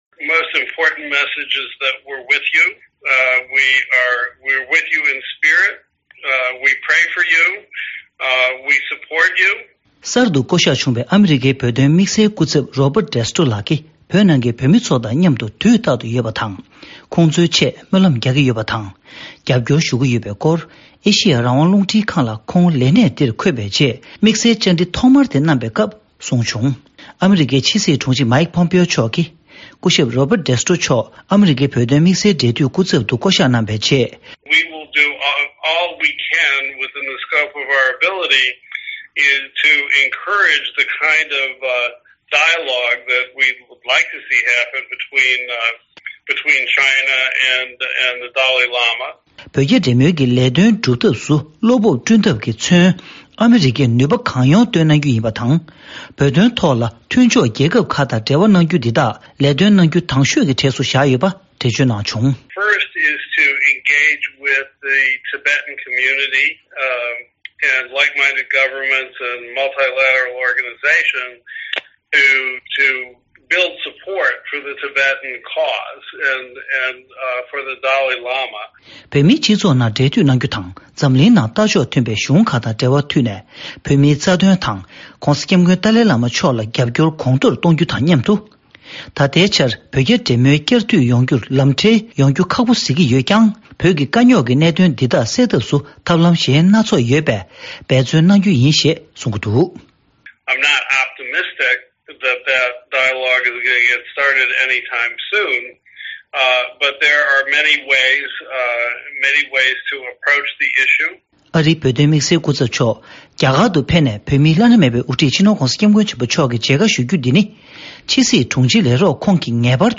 ཨ་རིའི་བོད་དོན་ཆེད་ཀྱི་དམིགས་བསལ་འབྲེལ་མཐུད་སྐུ་ཚབ་ལ་དམིགས་བསལ་བཅར་འདྲི།